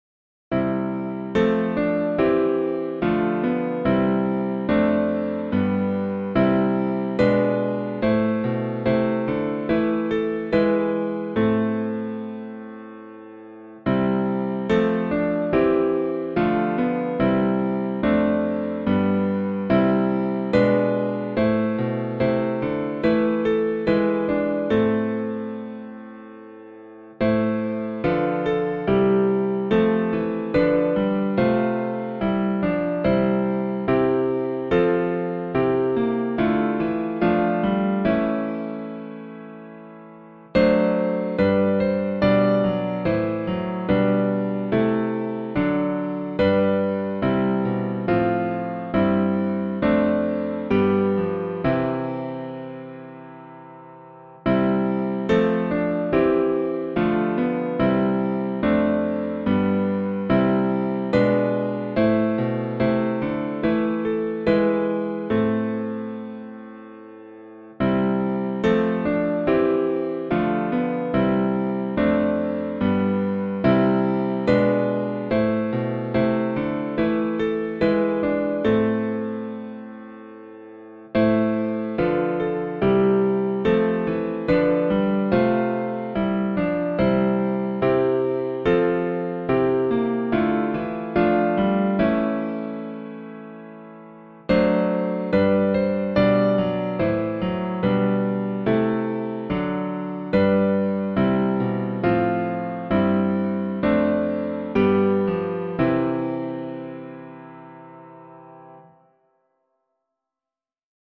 hymn tune
for piano